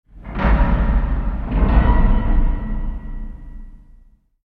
metal_bang.mp3